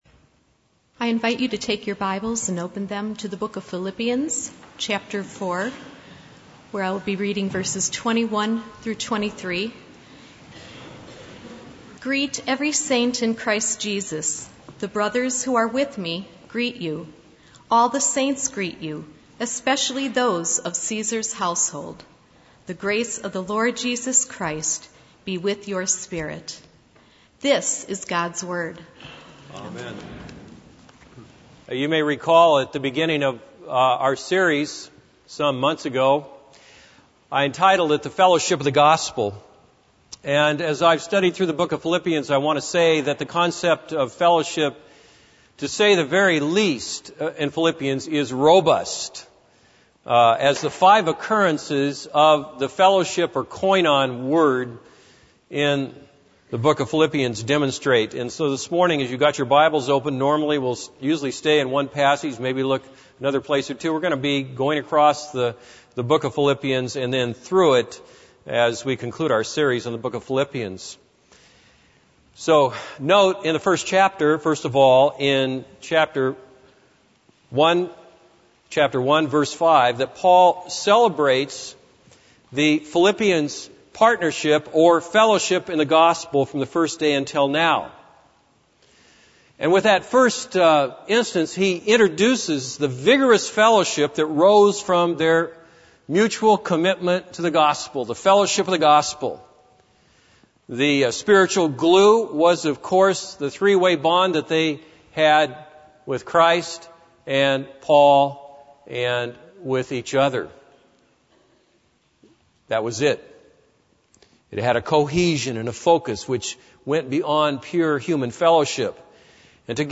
This is a sermon on Philippians 4:21-23.